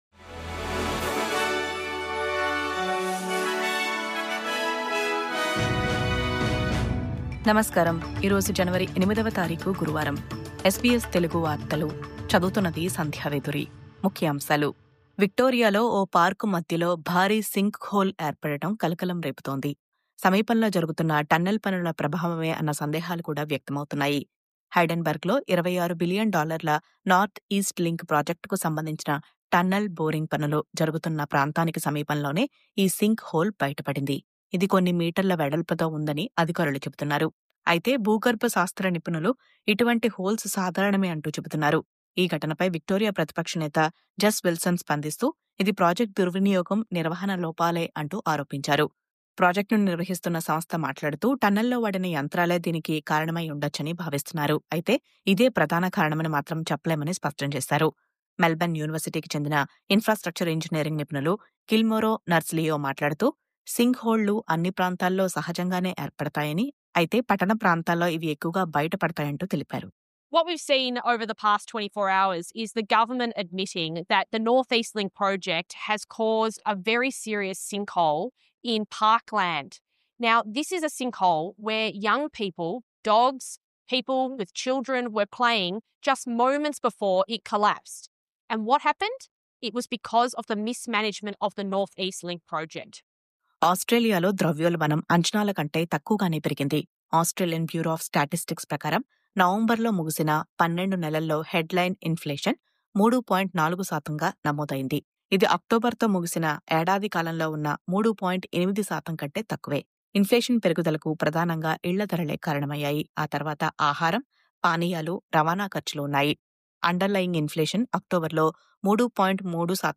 News update: విక్టోరియాలో పార్క్‌లో భారీ సింక్‌హోల్… టన్నెల్ పనులపై అనుమానాలు..